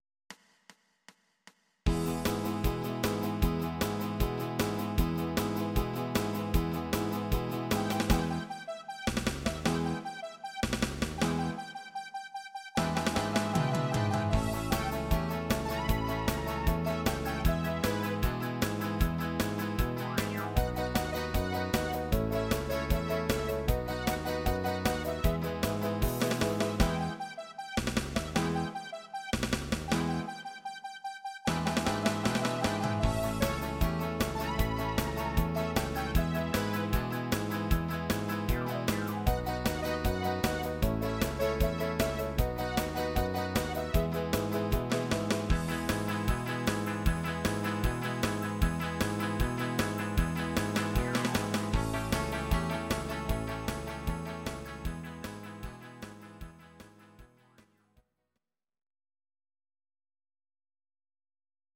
Audio Recordings based on Midi-files
Pop, Oldies, Country, 1960s